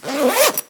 action_open_backpack_1.ogg